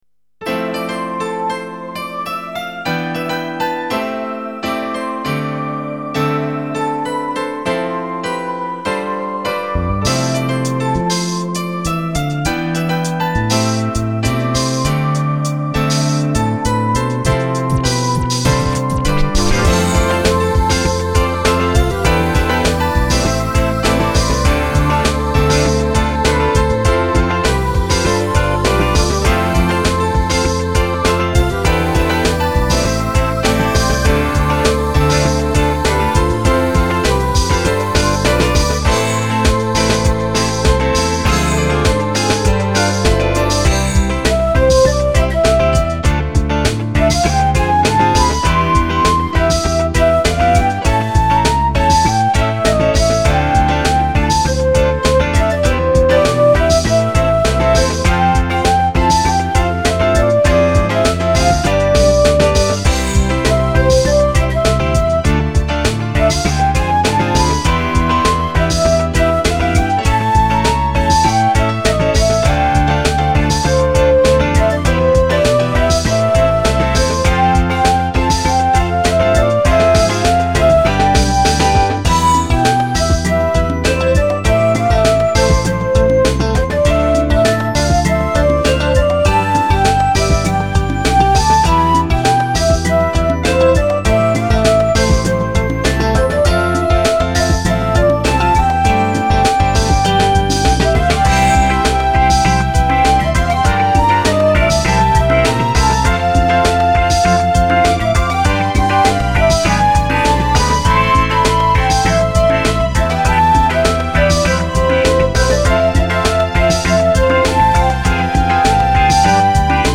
エフェクトや音量バランスはまだ要調整ですが。
メロ入り